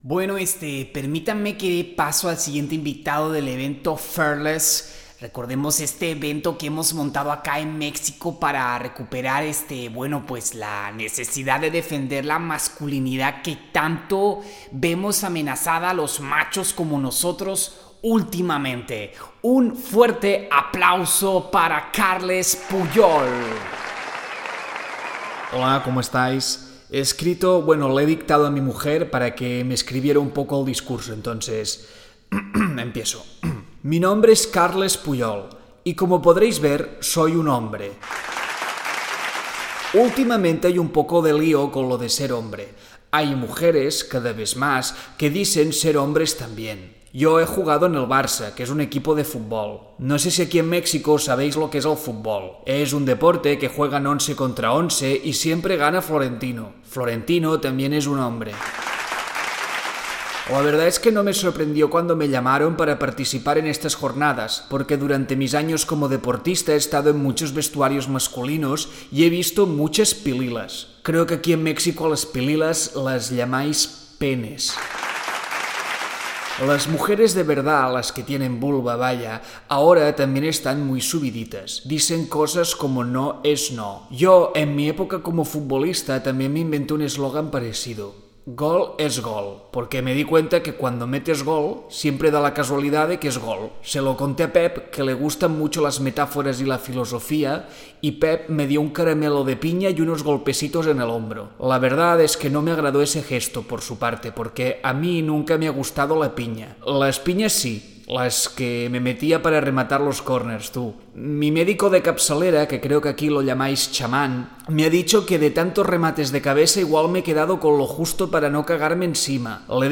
Santa Penya elabora pequeños sketches sobre la actualidad política que nos invade cada día.